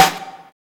SNARE RUGGED.wav